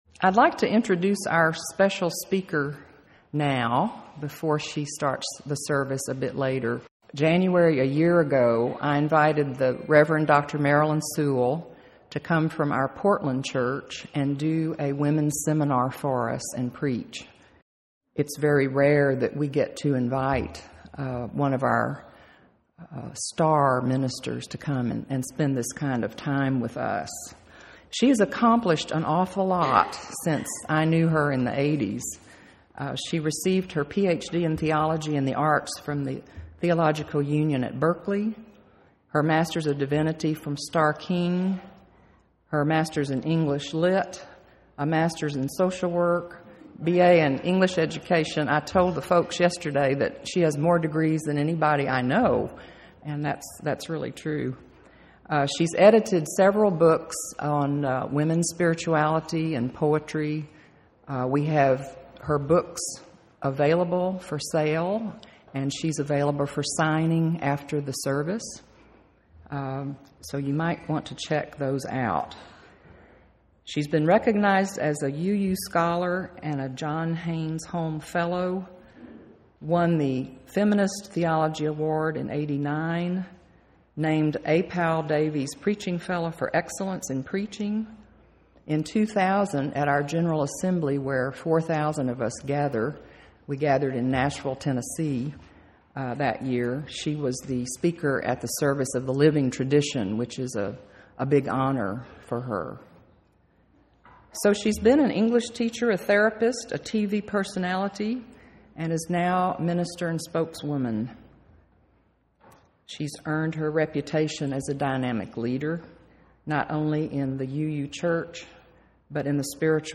2007 The text of this sermon is unavailable but you can listen to the sermon by clicking the play button.